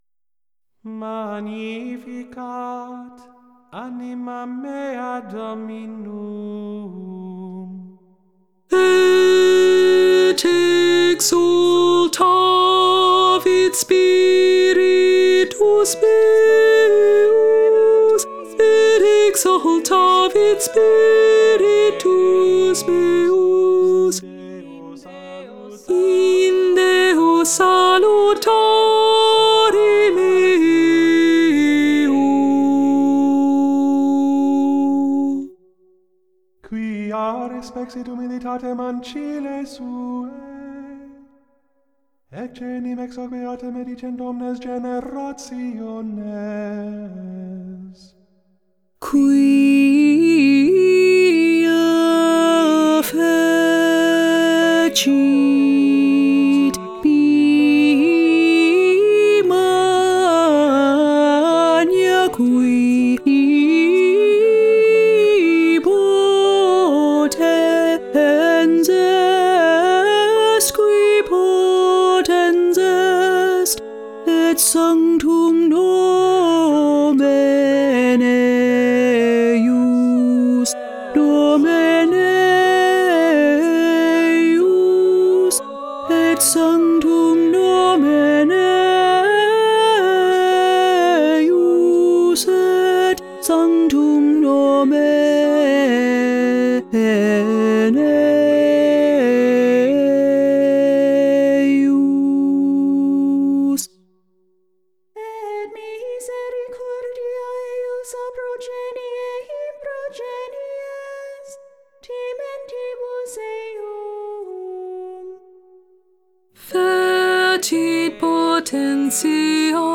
ALTO : YouTube   •
78576-ALTO-Guerrero-Magnificat.mp3